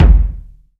Kicks
KICK.74.NEPT.wav